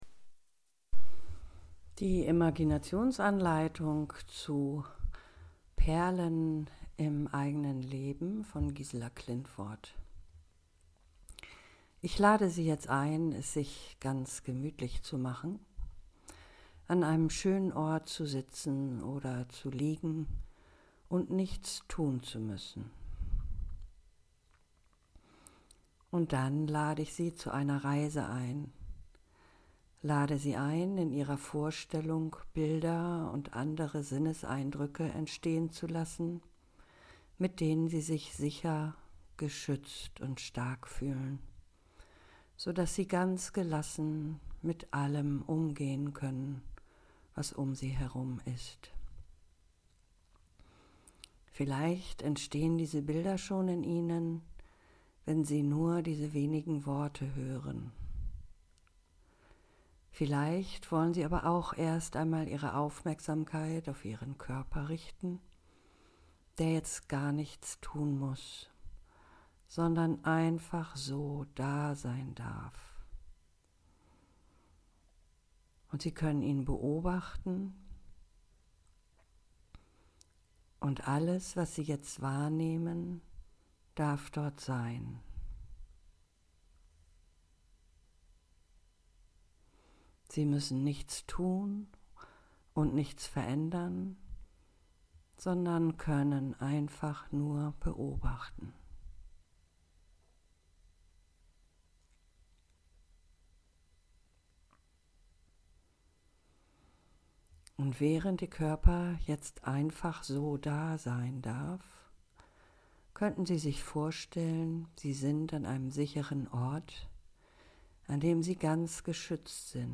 Für Menschen, die im Gesundheitswesen arbeiten und sich davon so belastet fühlen, dass sie nur schwer zur Ruhe kommen, findet sich hier eine Entspannungsanleitung zum Download (10 MB, 10:43 Minuten).